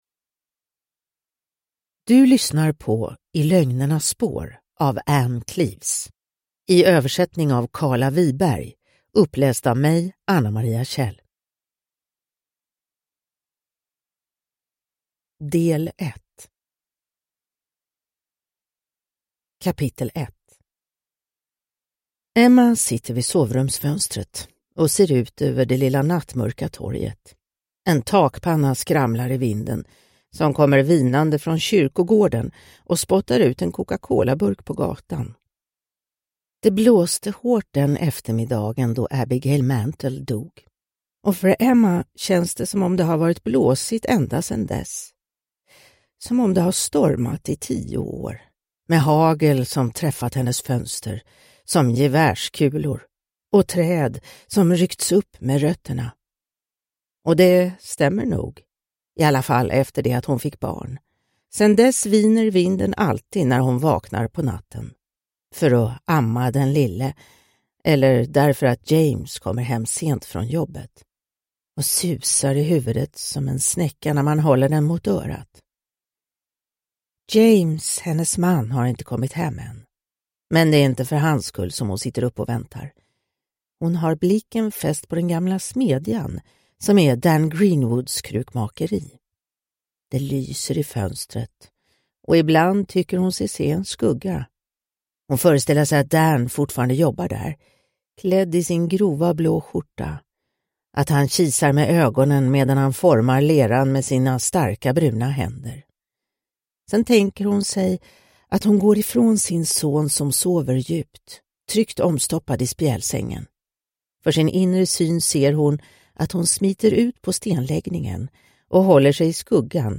I lögnernas spår – Ljudbok – Laddas ner